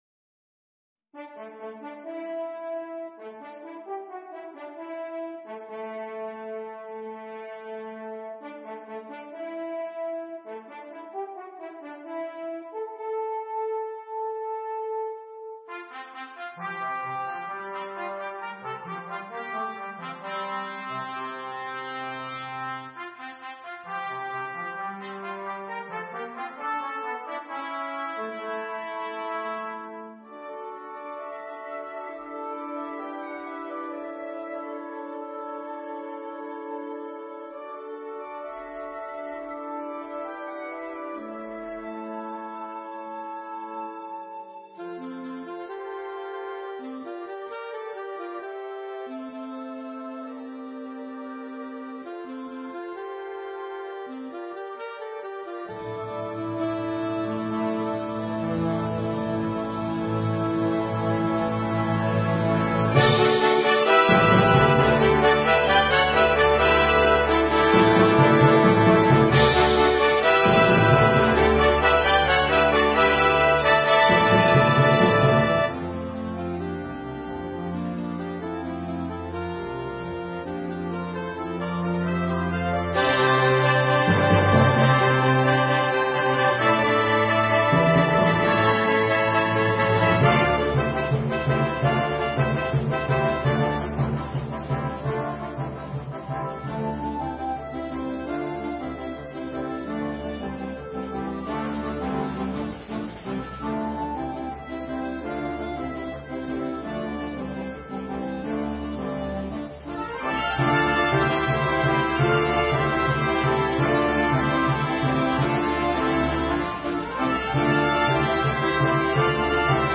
fantasy for band